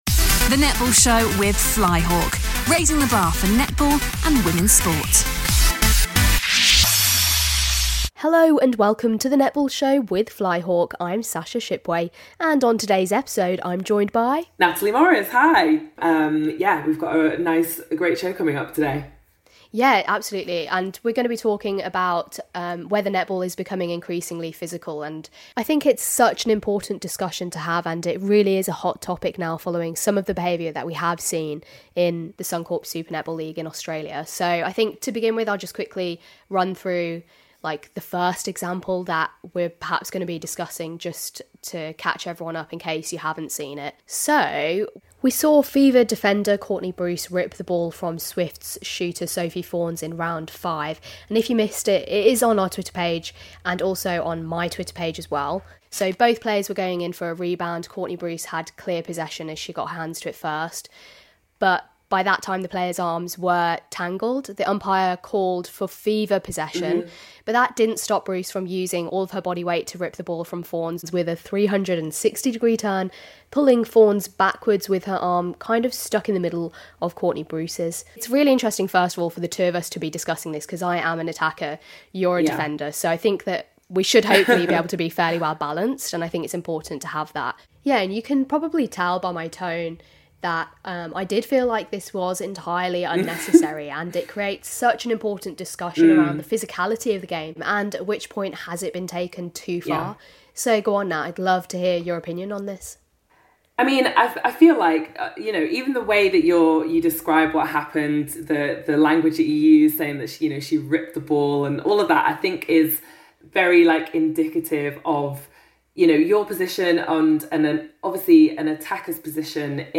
special documentary